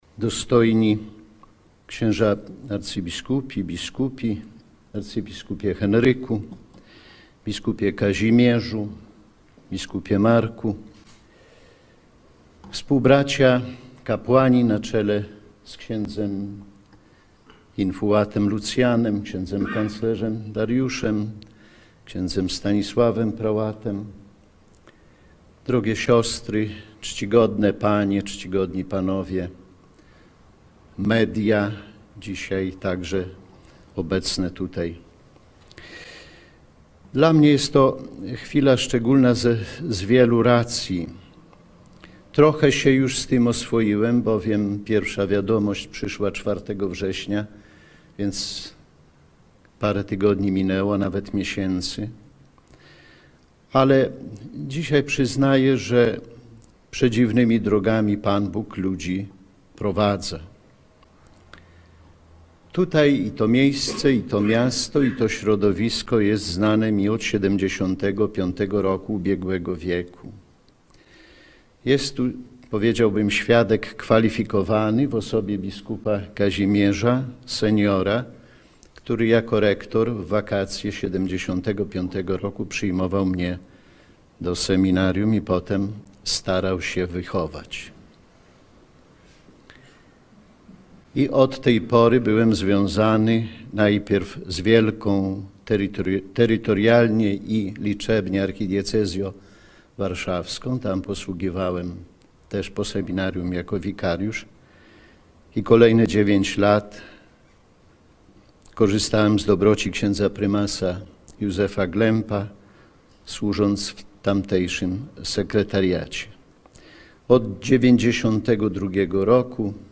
Do wysłuchania całość przemówienia bp. Kamińskiego podczas uroczystości w kurii warszawsko-praskiej.